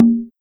BONGO-CONGA29.wav